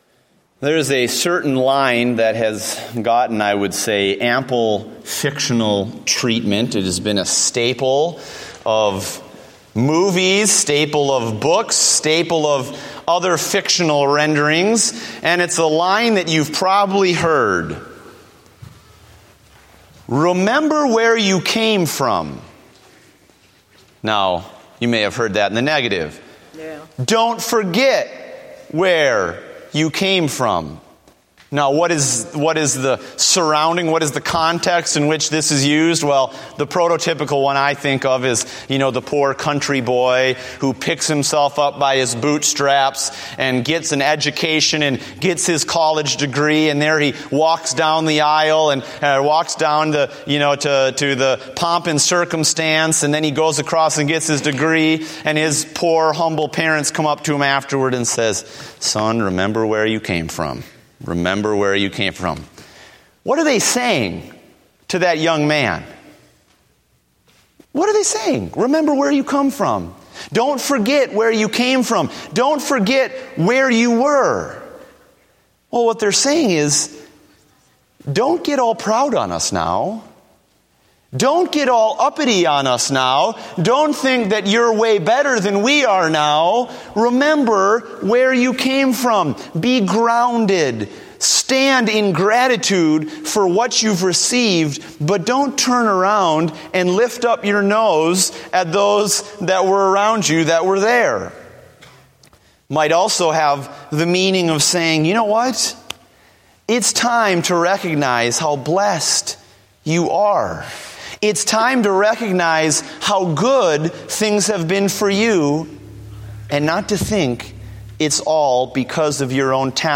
Date: November 23, 2014 (Evening Service)